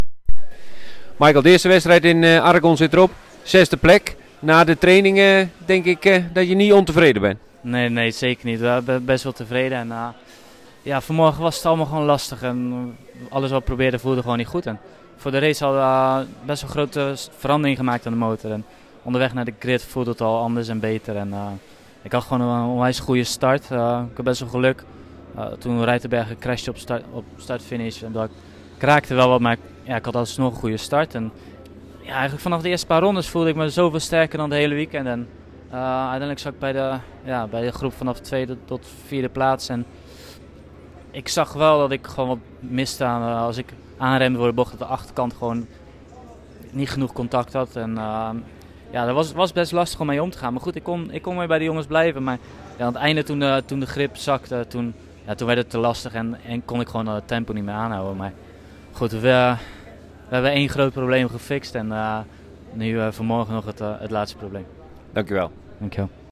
Na afloop van de race zochten we Van der Mark op en vroegen we hem naar een eerste reactie.